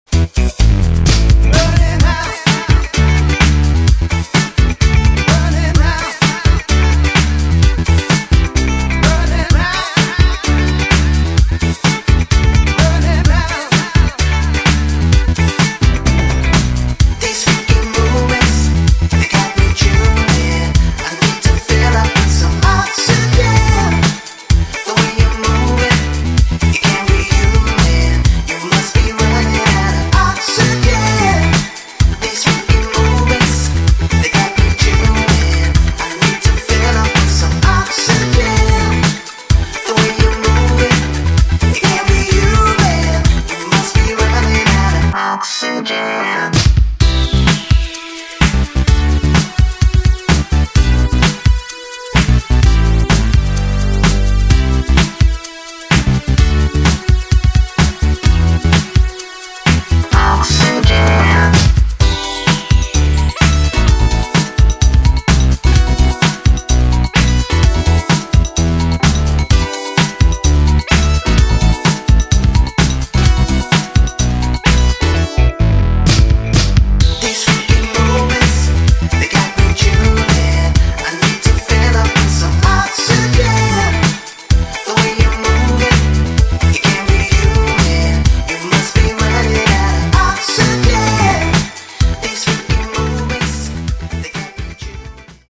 ...ELECTROFUNK